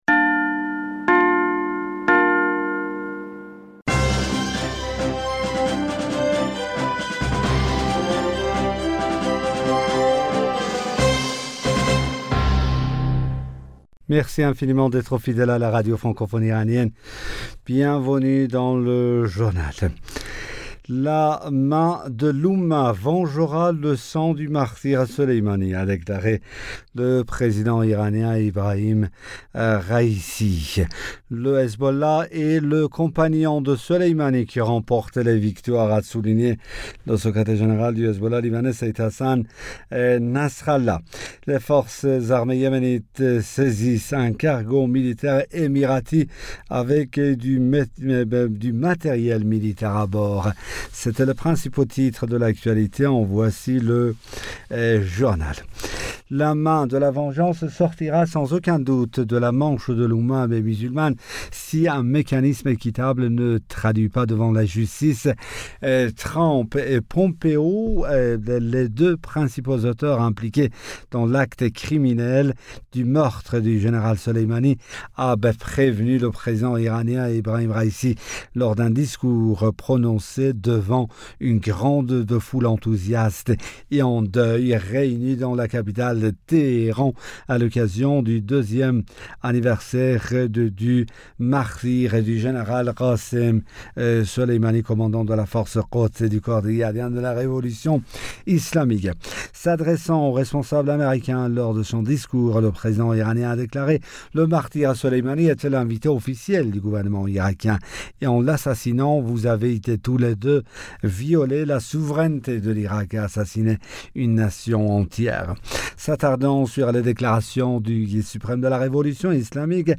Bulletin d'information Du 04 Janvier 2022